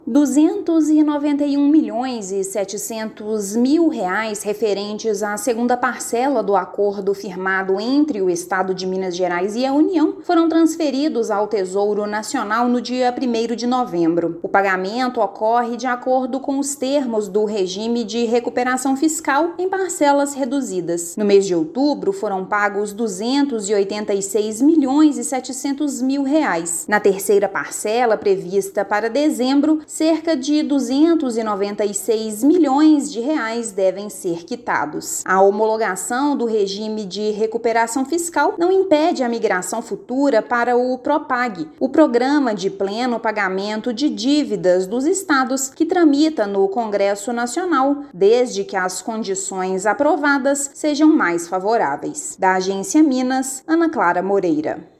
Na sexta-feira (1/11), foram transferidos R$ 291,7 milhões ao Tesouro Nacional. Ouça matéria de rádio.